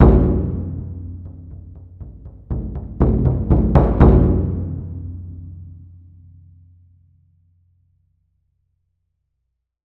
その響きを再現するために、TAIKO THUNDERはスタジオではなくホールでサンプリング収録を行いました。
• O.H.：オーバーヘッドのマイクポジションのサウンド（ステレオ）です。